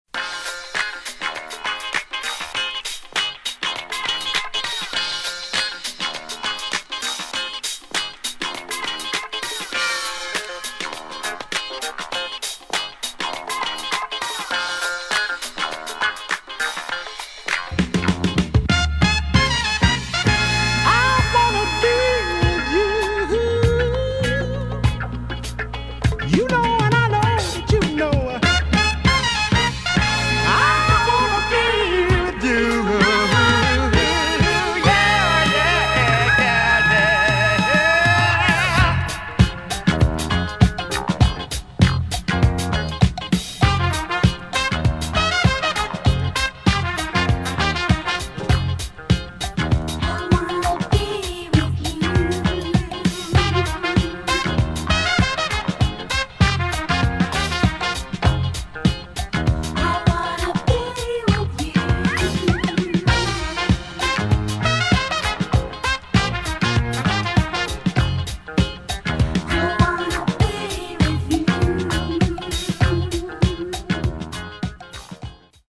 [ DISCO / EDIT / FUNK / SOUL ]